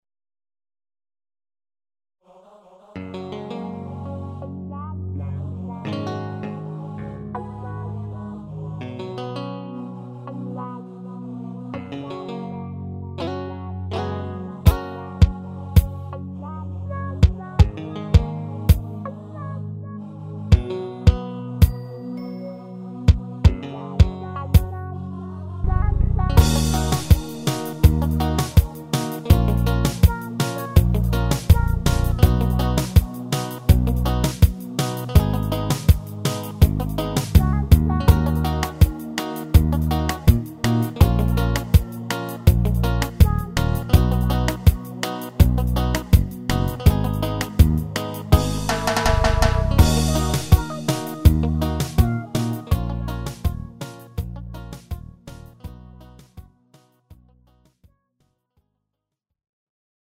MIDI Multitrack
01. Bajo – 230 notas
02. Guitarra E Clean – 543 notas
03. Guitarra E Jazz – 118 notas
04. Guitarra E Mute – 289 notas
06. Warm Pad – 169 notas
07. New Age Pad – 36 notas
08. Coros – 65 notas
10. Percusión – 984 notas